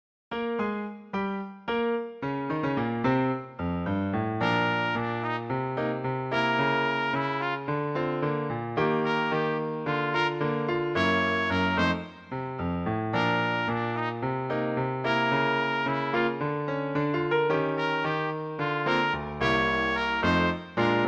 Swingová přednesová skladba pro trubku